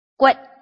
臺灣客語拼音學習網-客語聽讀拼-南四縣腔-入聲韻
拼音查詢：【南四縣腔】gued ~請點選不同聲調拼音聽聽看!(例字漢字部分屬參考性質)